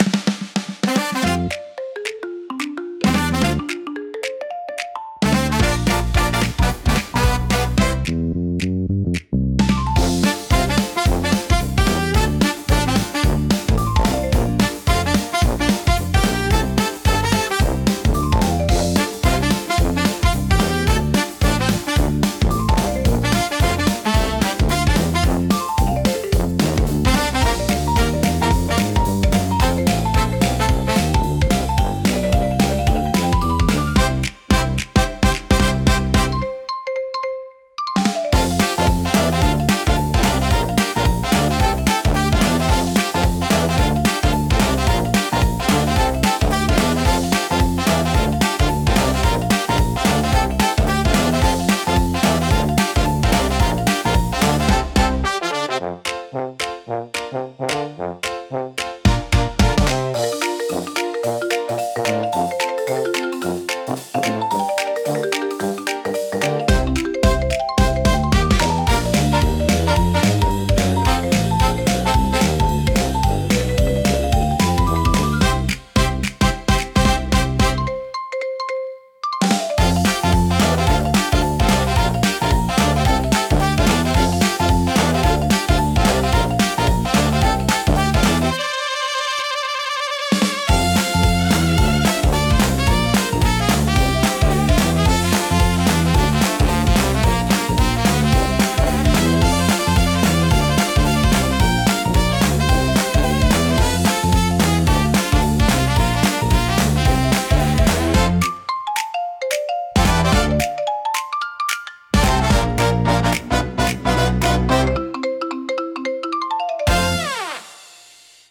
聴く人に軽やかで楽しい気分を届け、明るく気楽な空気を作り出します。